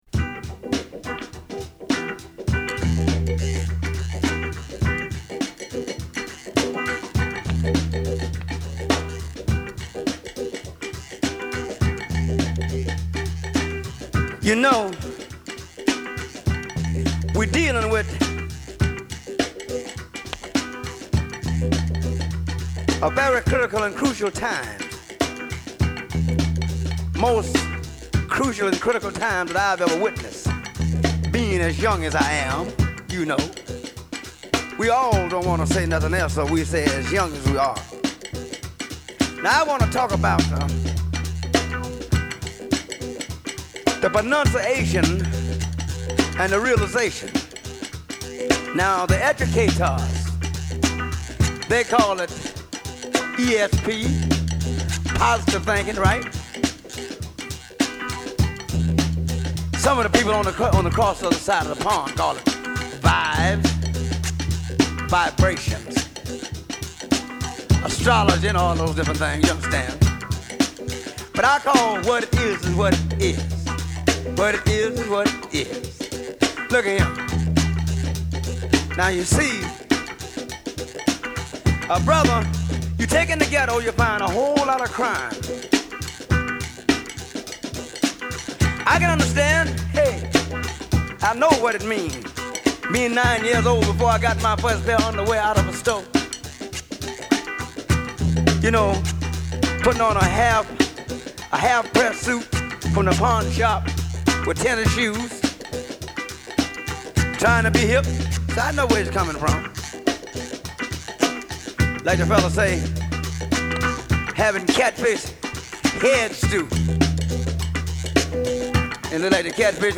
Genre : R&B.